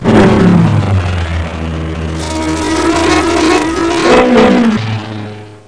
buzz3.mp3